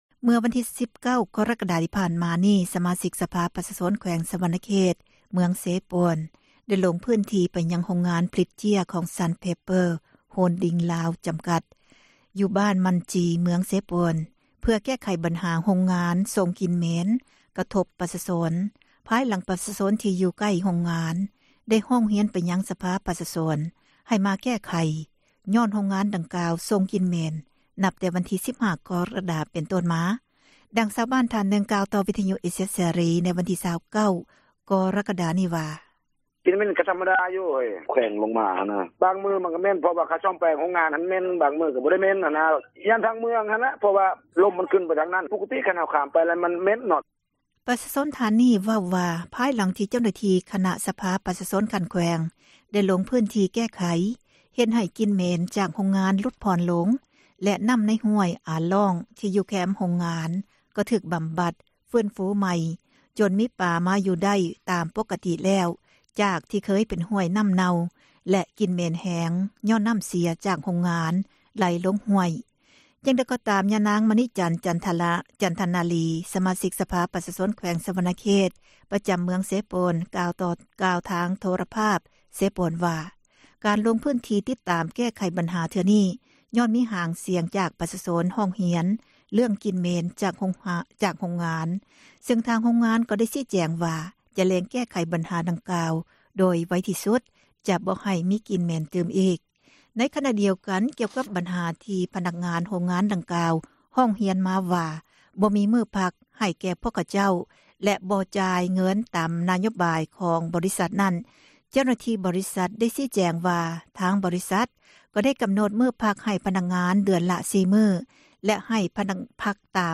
ເມື່ອວັນທີ 19 ກໍຣະກະດາ ທີ່ຜ່ານມາ ສະມາຊິກສະພາປະຊາຊົນ ແຂວງສວັນນະເຂດ ເມືອງເຊໂປນ ໄດ້ລົງພື້ນທີ່ ໄປຍັງໂຮງງານຜລິດ ເຈັ້ຽ ຊັນເປເປີ້ ໂຮນດິ້ງລາວ ຈໍາກັດ (Sun Paper) Holding Lao) ຢູ່ ບ້ານມັ່ນຈິ ເມືອງເຊໂປນ ເພື່ອແກ້ບັນຫາ ໂຮງງານສົ່ງກິ່ນເໝັນ ກະທົບປະຊາຊົນ ພາຍຫຼັງປະຊາຊົນທີ່ຢູ່ໃກ້ໂຮງງານ ໄດ້ຮ້ອງຮຽນໄປຍັງສະພາປະຊາຊົນ ໃຫ້ແກ້ໄຂຍ້ອນໂຮງງານ ດັ່ງກ່າວສົ່ງກິ່ນເໝັນ ນັບແຕ່ວັນທີ 15 ກໍຣະກະດາ ເປັນຕົ້ນມາ, ດັ່ງຊາວບ້ານ ທ່ານນຶ່ງເວົ້າຕໍ່ ວິທຍຸເອເຊັຽເສຣີ ໃນວັນທີ່ 29 ກໍຣະກະດາ ນີ້ວ່າ: